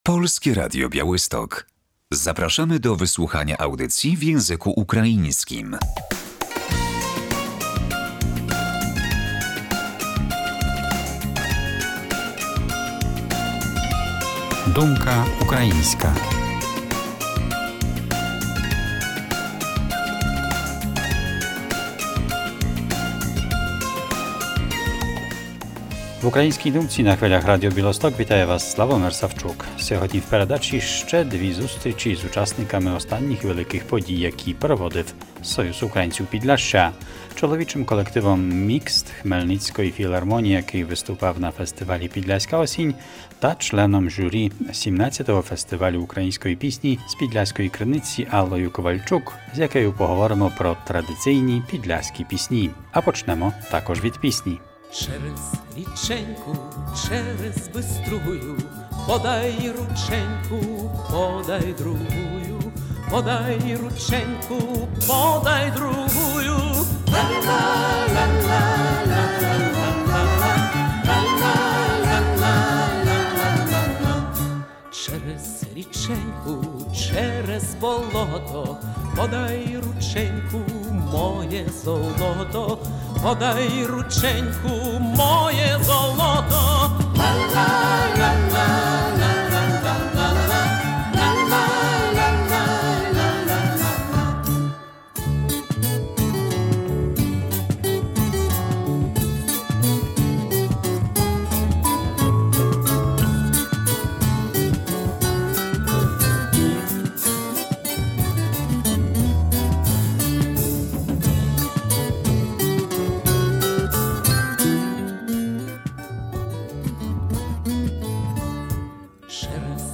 Zespół wokalny "Mikst" na Podlasiu 03.11.2021